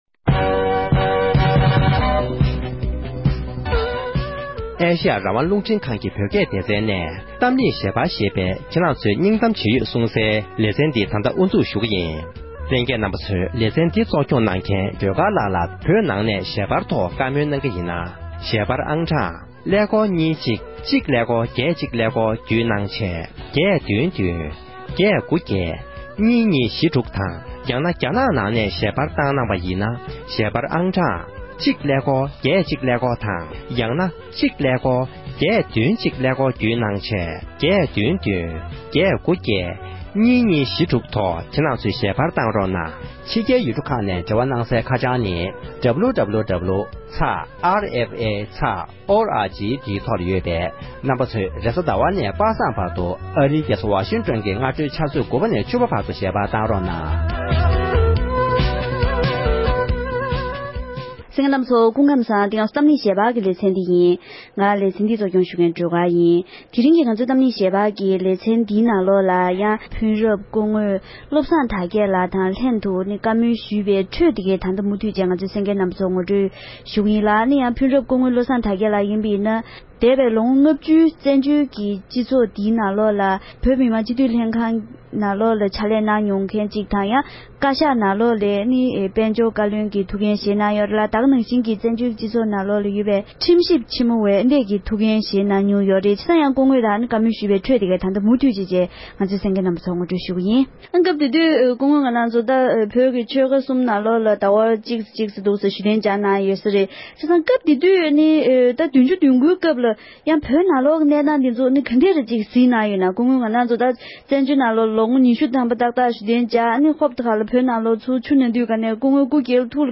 བཙན་བྱོལ་བོད་གཞུང་གི་ཐུགས་འགན་བཞེས་མྱོང་མཁན་གྱི་མི་སྣའི་ལྷན་དུ་གླེང་བ།